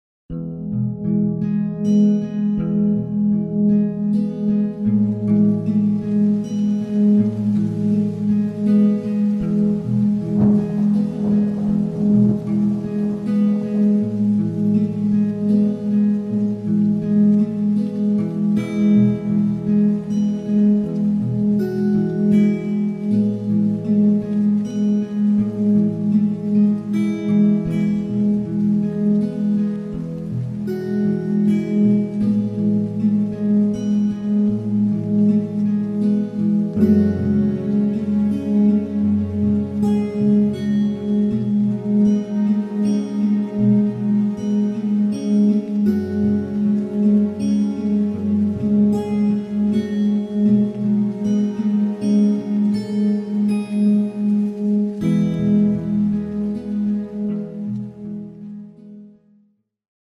דוגמת מוזיקה בקלטת עם תדר בינוראלי לשגשוג:
דוגמת פס קול בקלטת למצוא עבודה עם תדר בינוראלי